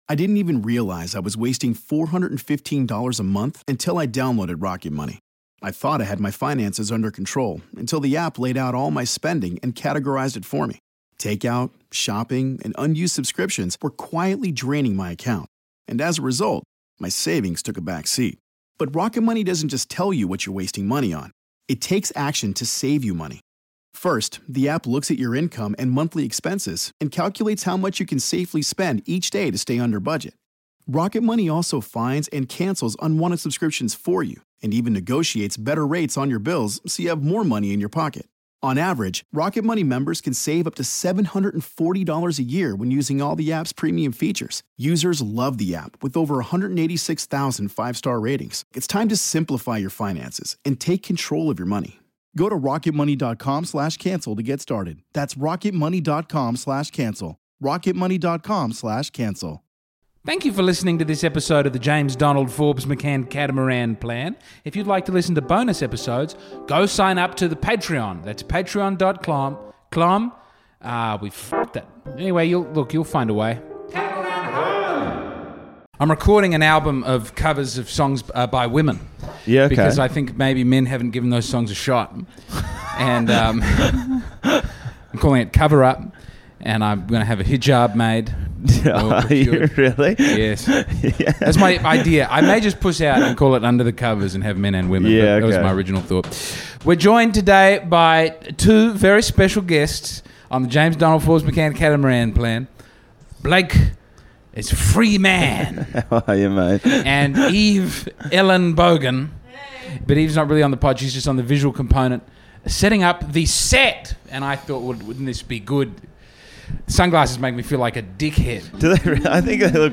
Other wise I assume it'll be a normal podcast with some annoying hammering sounds.